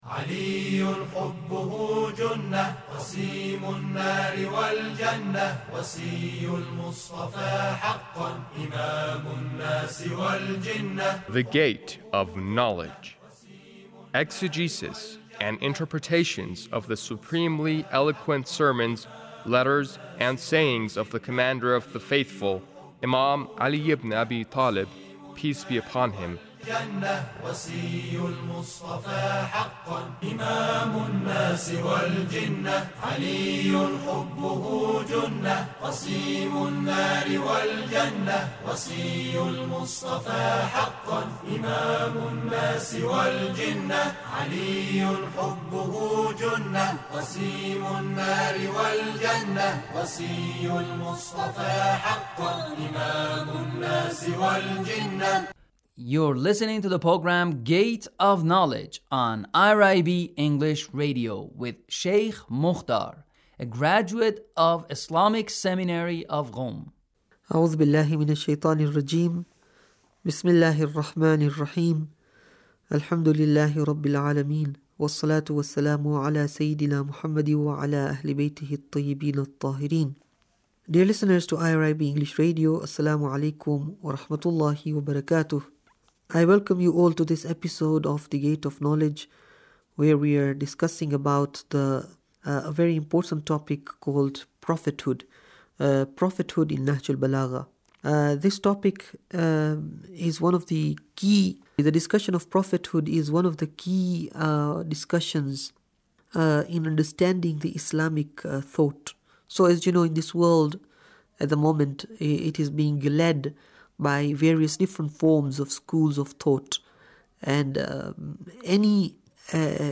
Sermon 1 - Prophethood 1